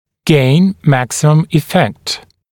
[geɪn ‘mæksɪməm ɪ’fekt][гейн ‘мэксимэм и’фэкт]получить максимальный эффект, добиться максимального эффекта